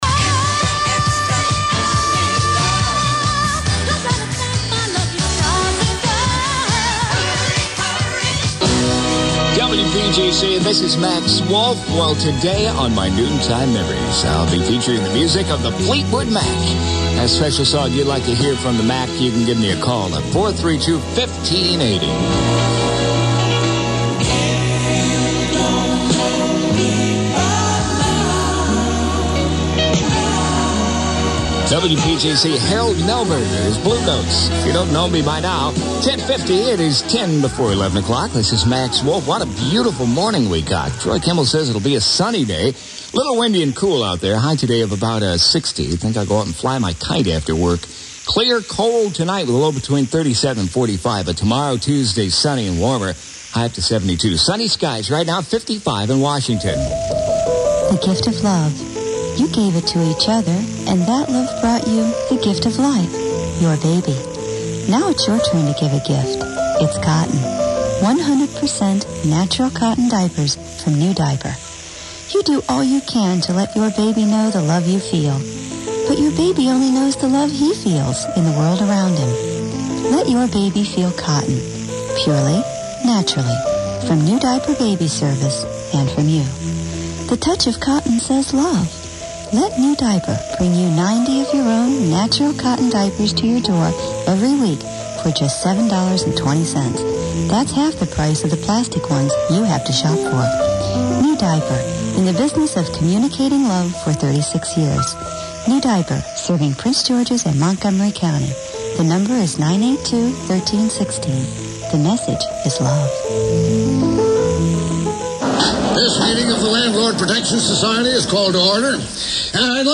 In this tape which covers approximately an hour, only a total of three commercials are heard!
Station promos on this aircheck consist of two 'People Promos'.
Listen as listeners are pathetically prompted, word for word what to say on these.
Jingles were from JAM's 'Double Plus' package created originally for WYNY in New York.